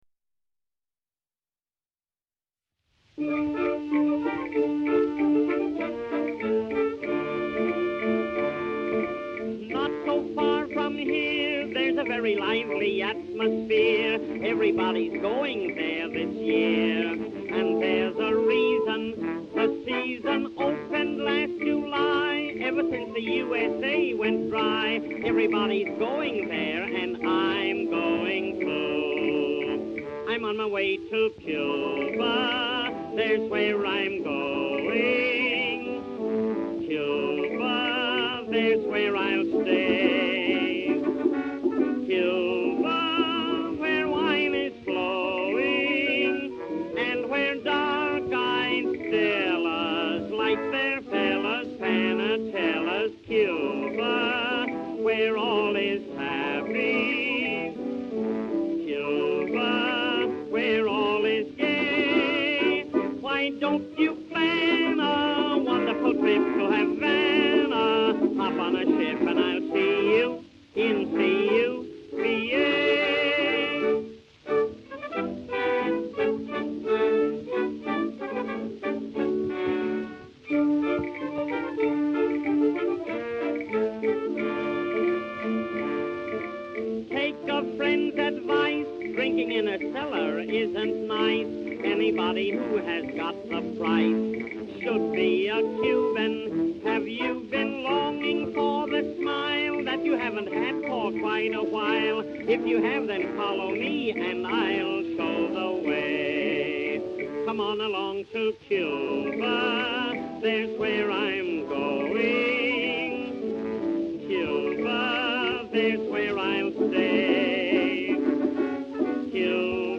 78 RPM Record